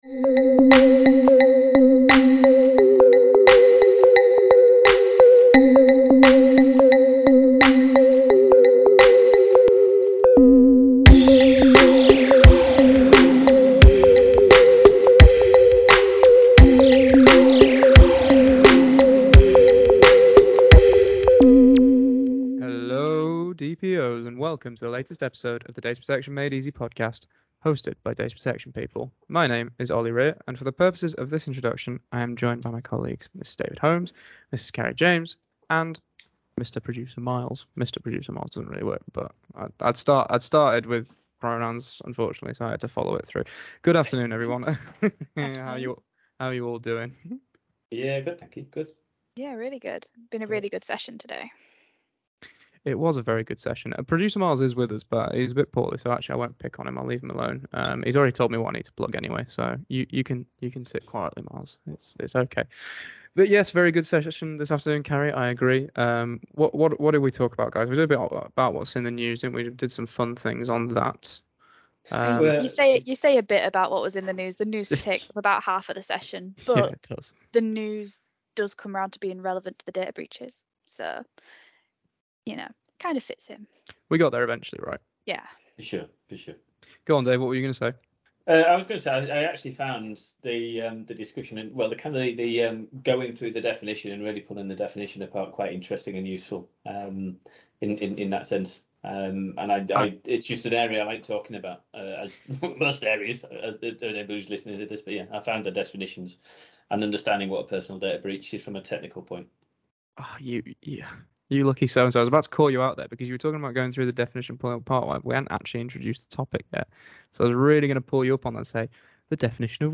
These recordings originally take place in the form of an interactive webinar with hundreds of live listeners every week, you can post comments in the chat, unmute and get involved, it's a time for data protection people to share their frustrations and find solutions to shared challenges.